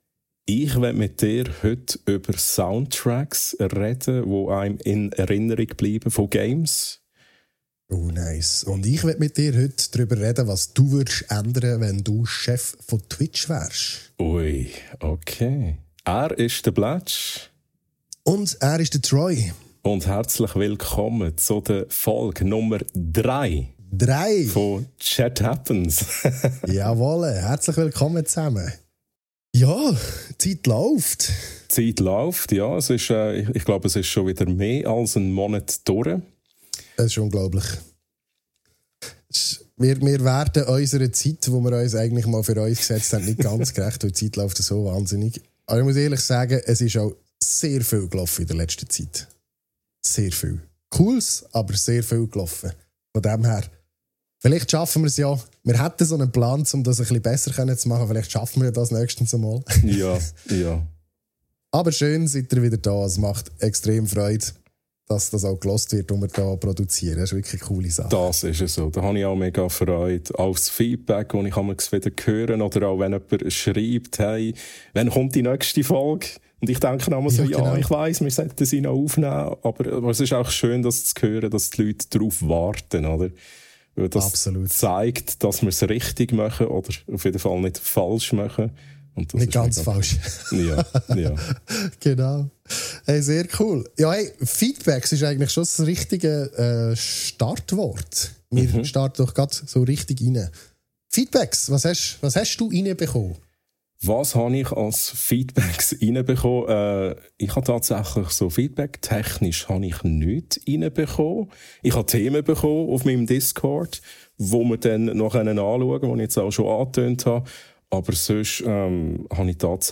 Schweizerdeutsch, verspielt und ehrlich – viel Spass mit Episode 3 von «Chat Happens»!